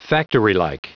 Prononciation du mot : factorylike
Entrez un mot en anglais, et nous le prononcerons pour vous.